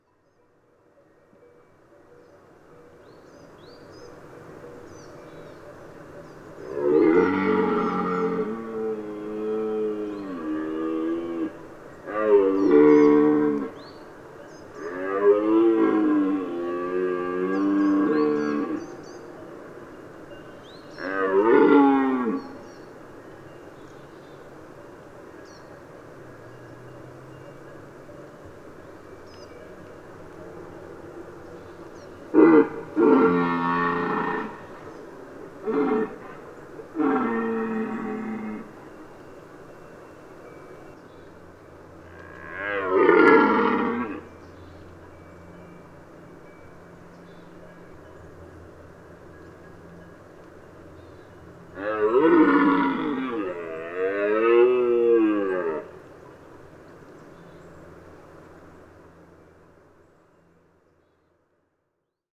Les grands cerfs s’expriment avec un timbre plus grave et plus spectaculaire que les autres car leurs poumons et leur poitrine fonctionnent comme une caisse de résonance.
Pour enregistrer les sons du brame, j’ai utilisé un microphone parabolique très sensible, veillant à garder une distance de sécurité pour éviter de perturber les rituels d’accouplement.
Brame-du-cerf-1-1.mp3